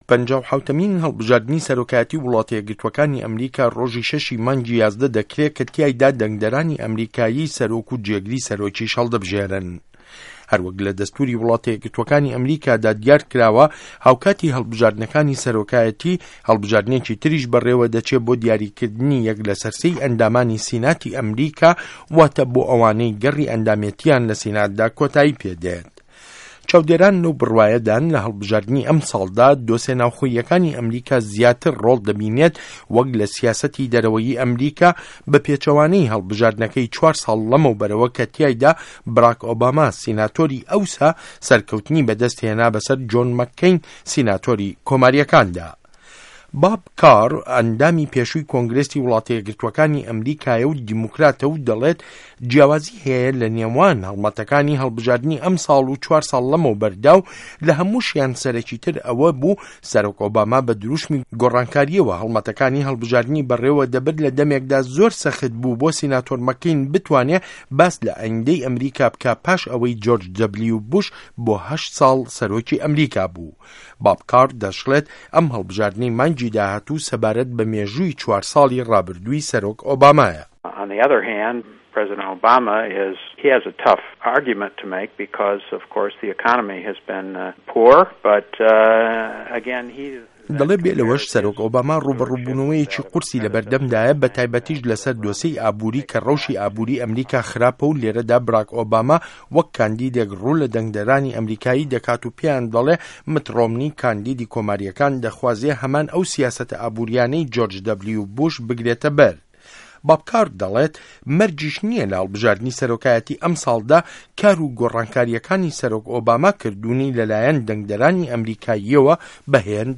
ڕاپۆرت له‌سه‌ر لێدوانه‌کانی بۆب کار